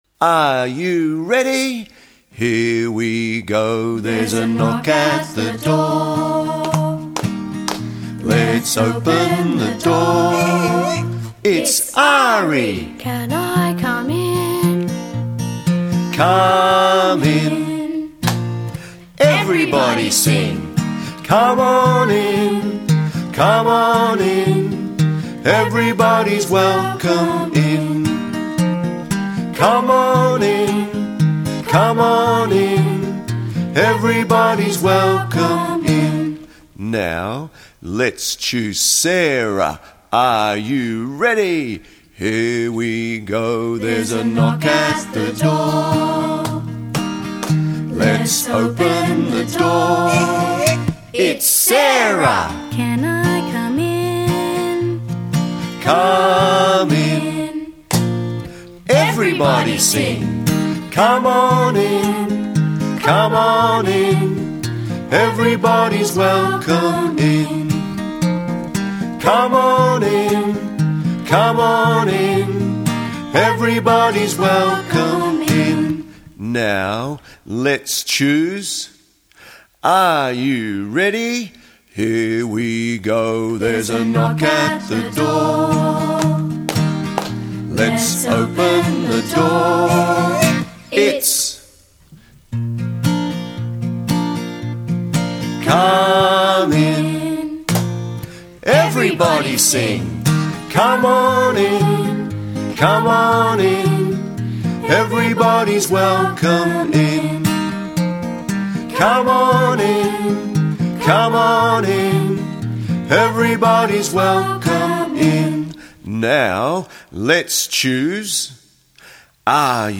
Keeping the beat Welcoming Songs